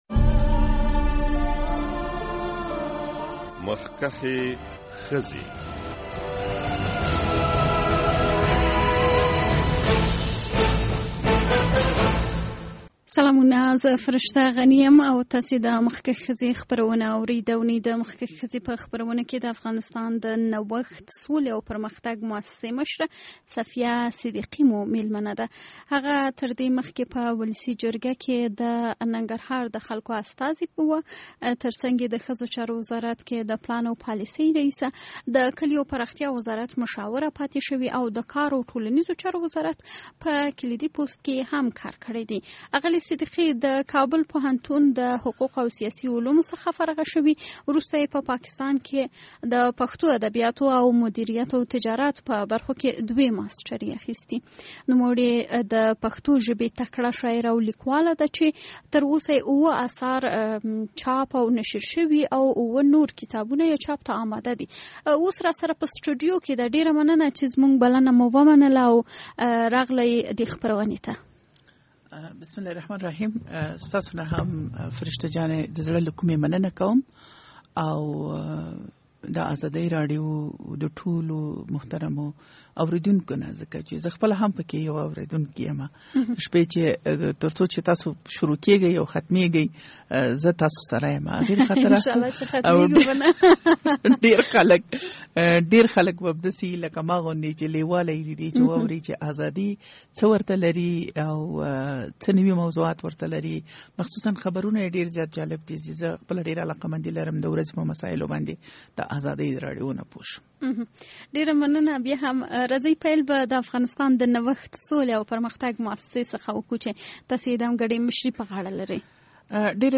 د دې اوونۍ د مخکښې ښځې په خپرونه کې د افغانستان د نوښت، سولې او پرمختګ موسسې مشره صفیه صدیقي مو مېلمنه ده.